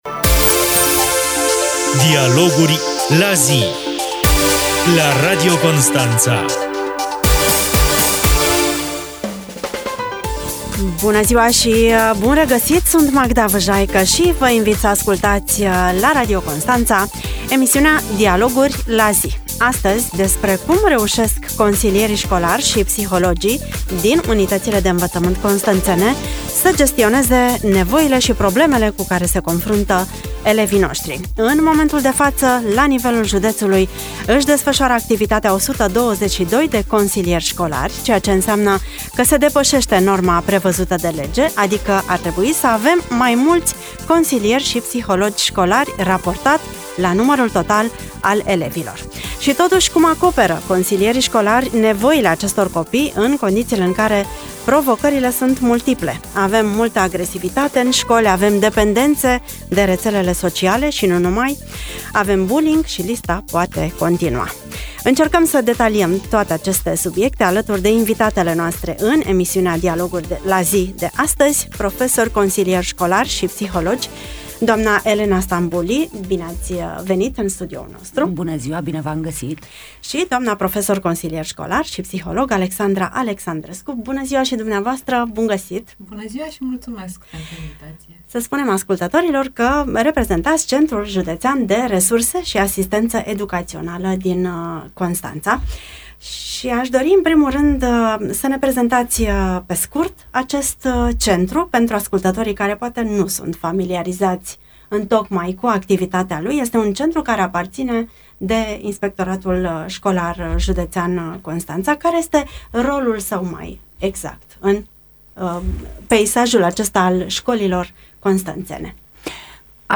Emisiunea „Dialoguri la zi” poate fi ascultată aici: Share pe Facebook Share pe Whatsapp Share pe X